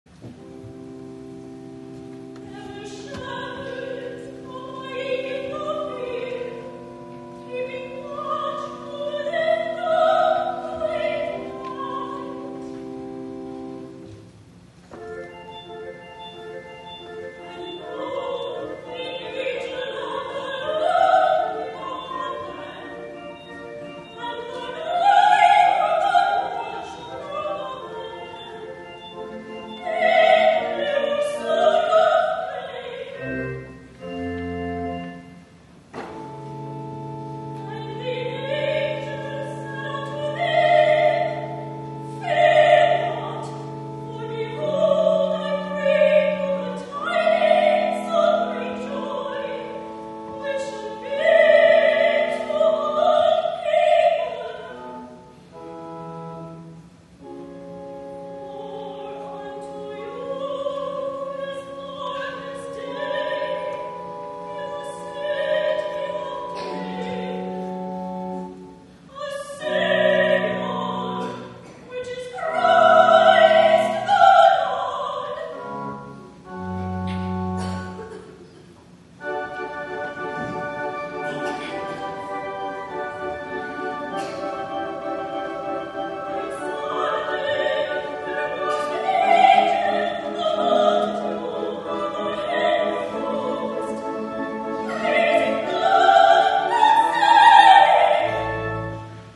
THE MUSICAL OFFERING MESSIAH (1741) George Frideric Handel
8. Recitative:
soprano